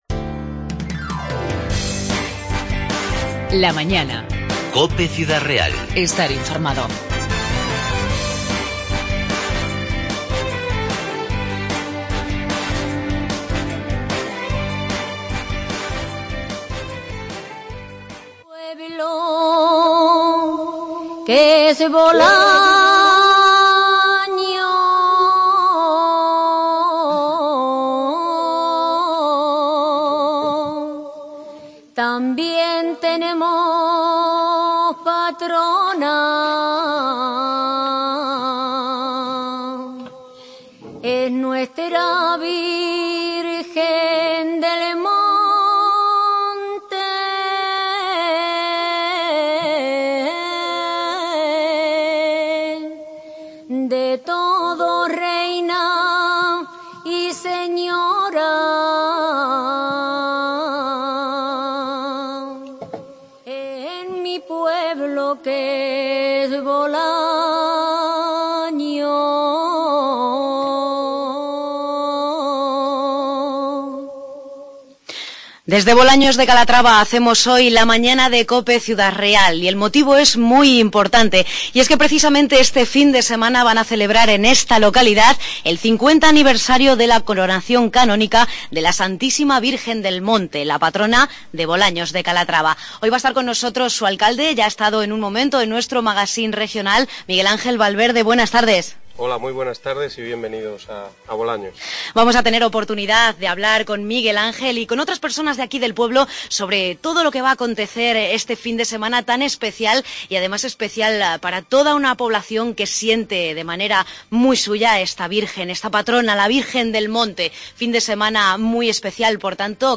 La Mañana de COPE Ciudad Real desde Bolaños 20-5-16 primera parte